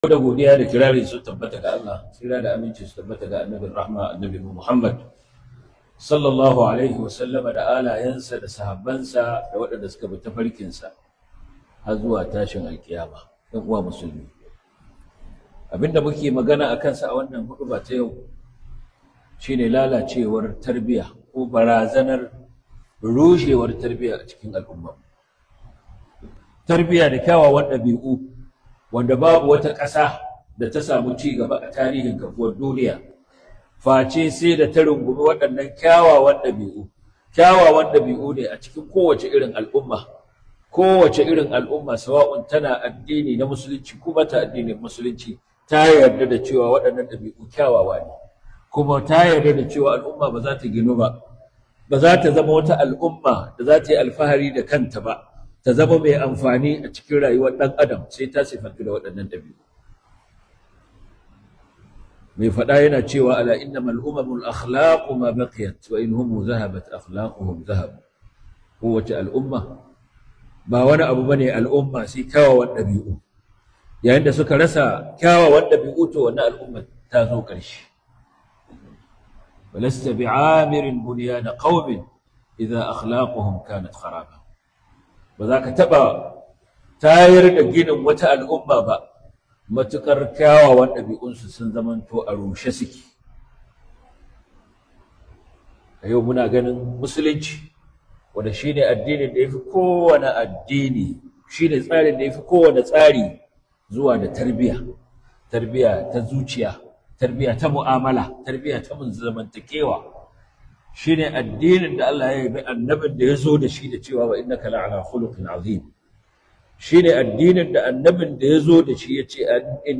Barazanar Tabarbariwar Tarbiya -2025-11-21 - HUDUBA